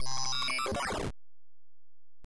Robot
Sound Effect
This is a robot
This was made with bfxr, lol back in 2013
robot_0.mp3